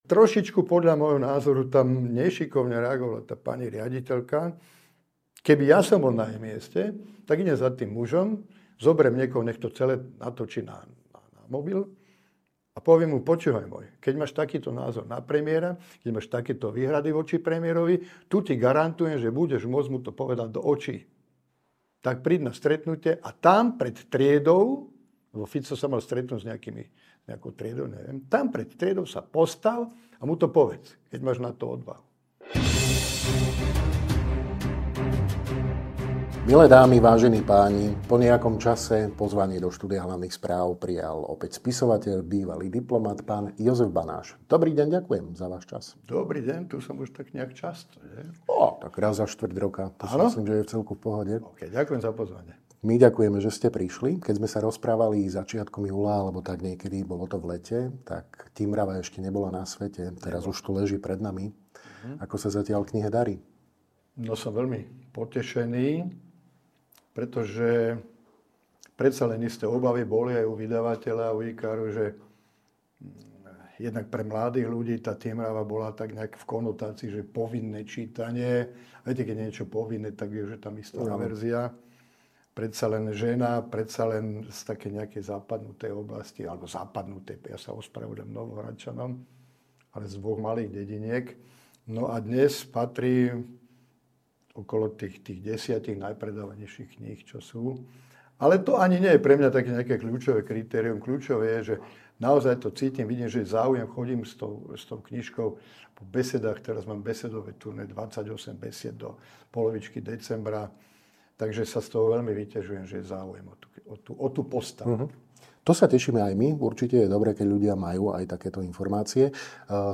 Dozviete sa vo videorozhovore s jedným z najúspešnejších slovenských spisovateľov, bývalým politikom, Ing. Jozefom Banášom.